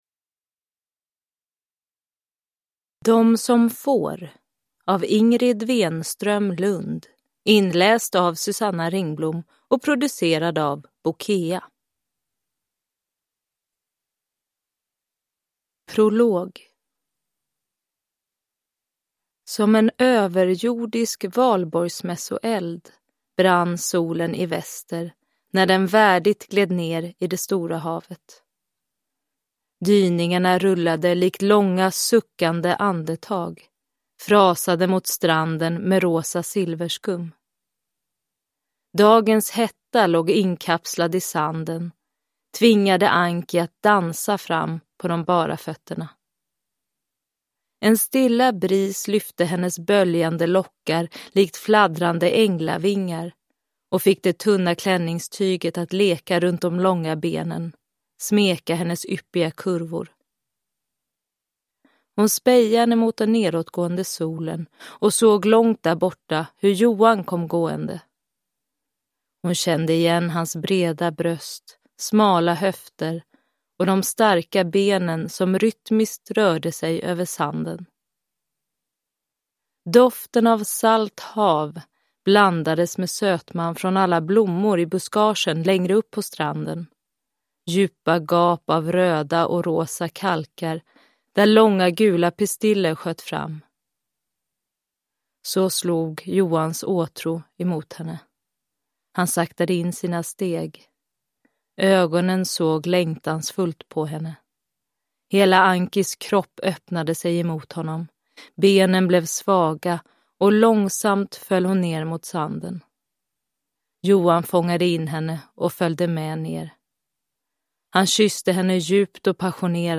De som får – Ljudbok